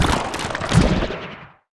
sfx_skill 01.wav